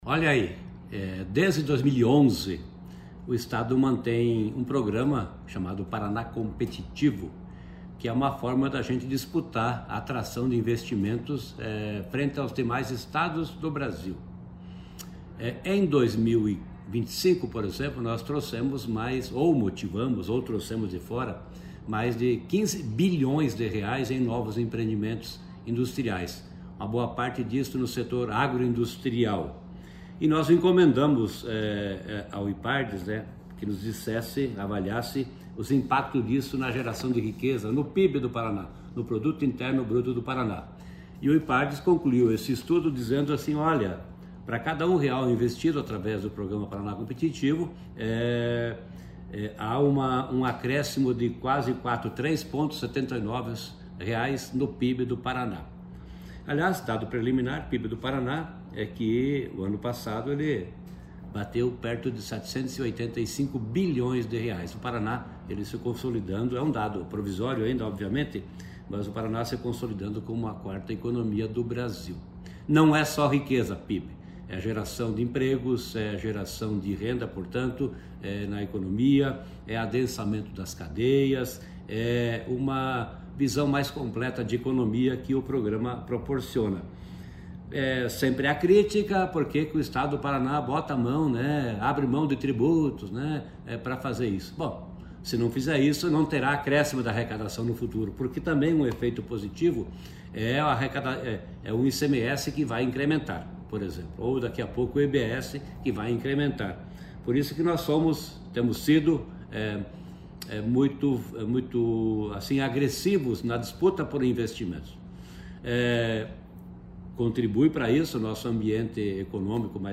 Sonora do secretário Estadual da Fazenda, Norberto Ortigara, sobre o retorno do programa Paraná Competitivo ao PIB do Estado